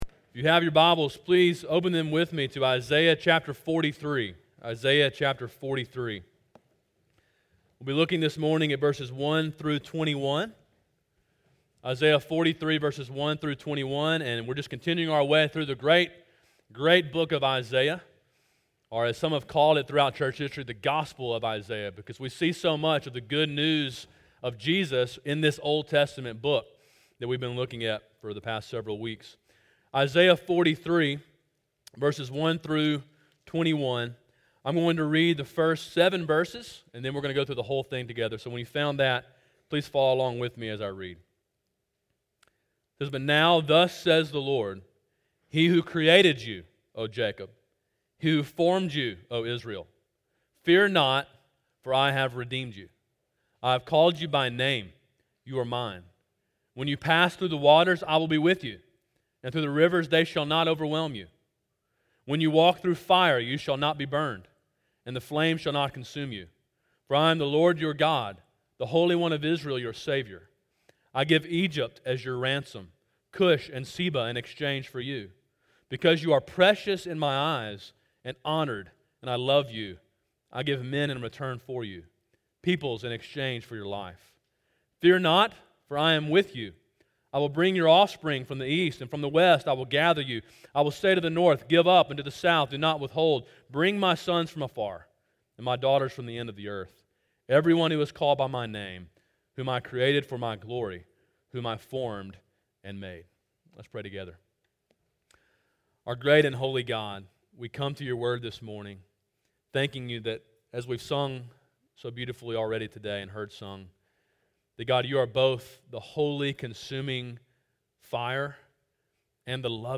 Sermon: “Redeemed” (Isaiah 43:1-21)